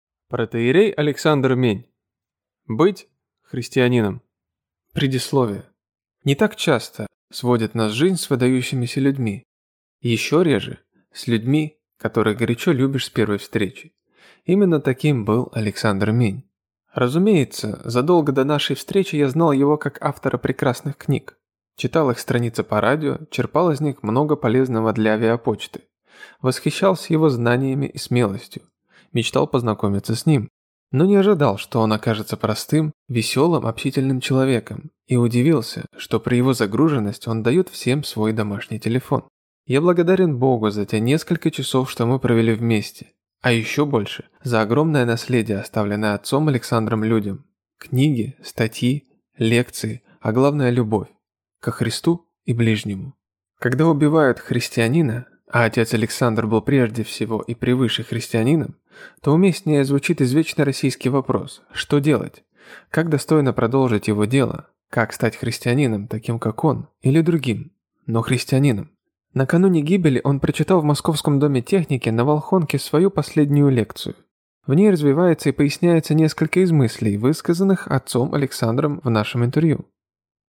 Aудиокнига Быть христианином